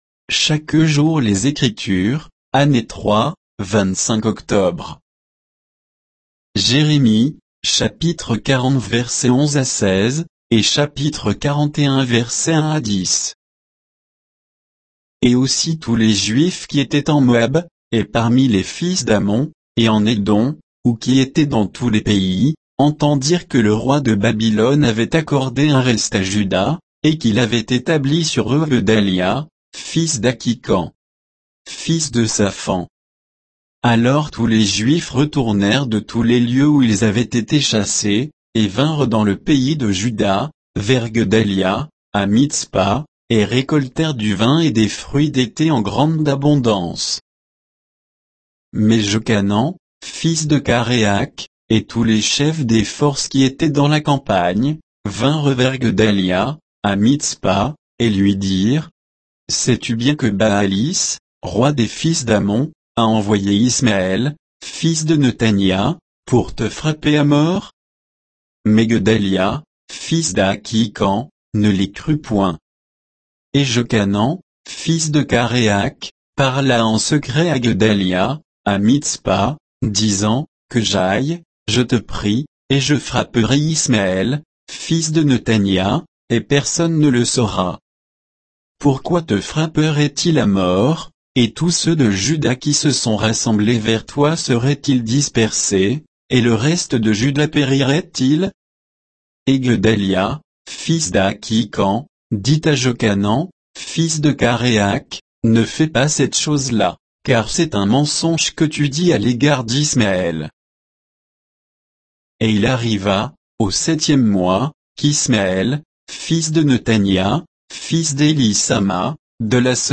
Méditation quoditienne de Chaque jour les Écritures sur Jérémie 40